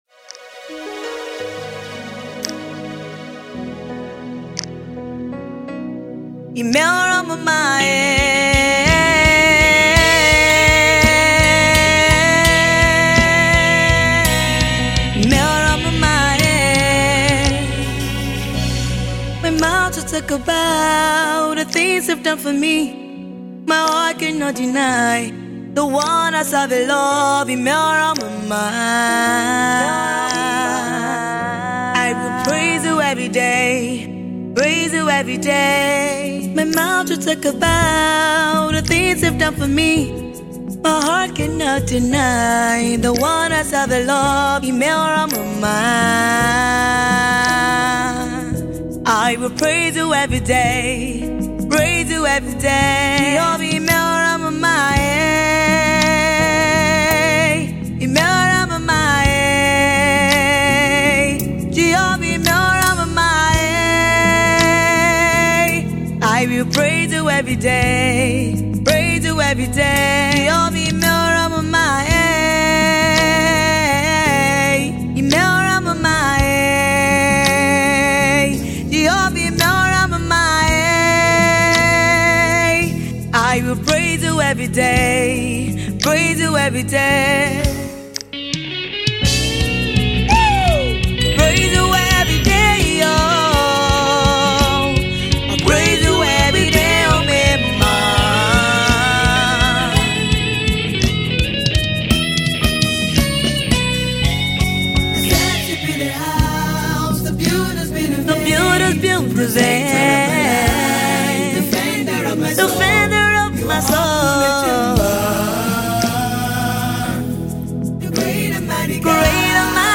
Gospel Music